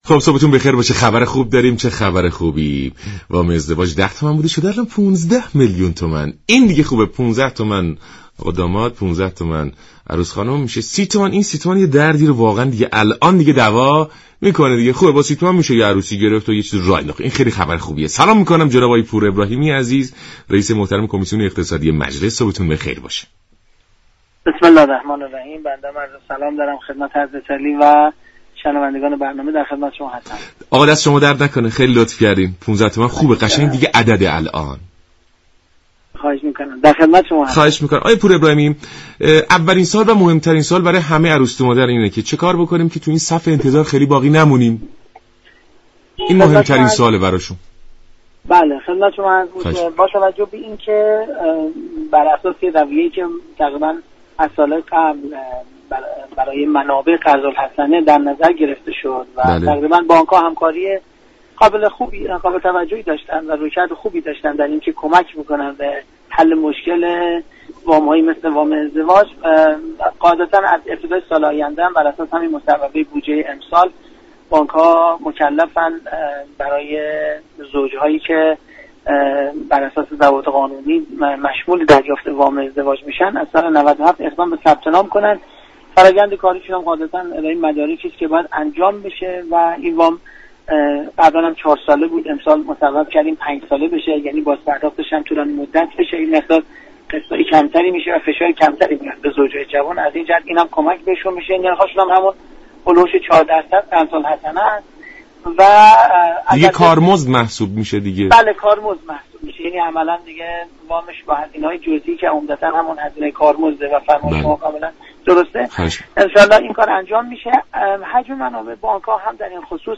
رییس كمیسیون اقتصادی مجلس در گفت و گو با برنامه «سلام ایران» گفت: سال 97 بانك ها بر اساس مصوبه بودجه سال جدید موظفند برای زوج هایی كه طبق مقررات مشمول دریافت ازدواج هستند؛ شرایط ثبت نام را فراهم نماید.